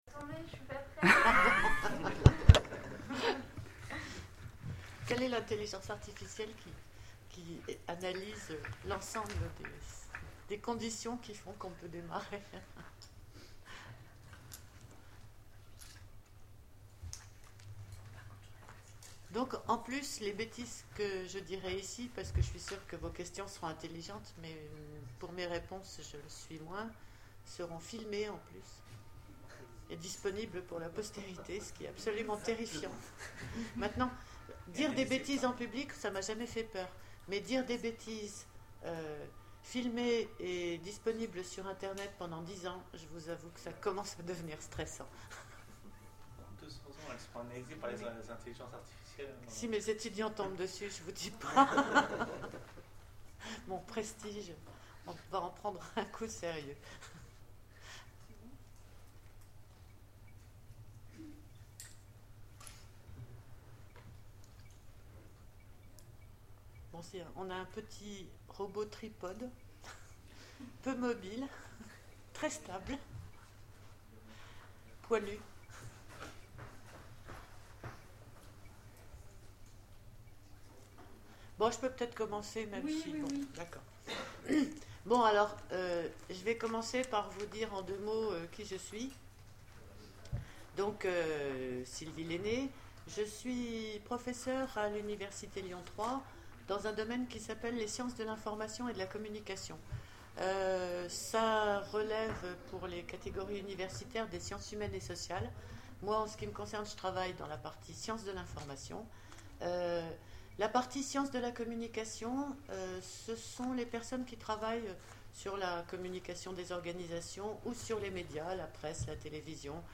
Utopiales 12 : Cours du soir Les Intelligences Articifielles dans la science-fiction
Mots-clés Intelligence artificielle Conférence Partager cet article